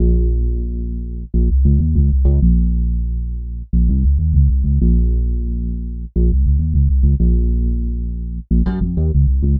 Bass 45.wav